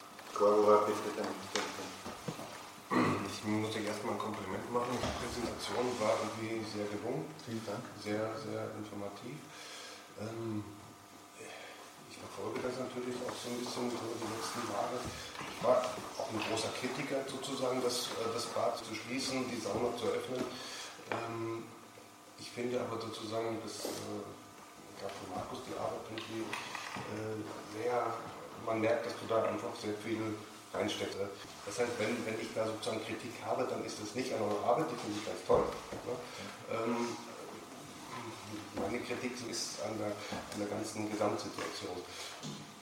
Dass die Gemeinderatssitzung von uns und der SPÖ Würflach dazu genutzt wurde, auf sachlicher Ebene Fragen zu stellen, die über die Farbe der Sonnenliegen hinausgehen, hätte auch die Würflacher ÖVP nicht überraschen dürfen.
Unser Gemeinderat Dr. Karl Lorber lobte in der Sitzung sogar ausdrücklich die Arbeit der Geschäftsführung und betonte, dass sich die Kritik vielmehr an das Konzept als solches richtet (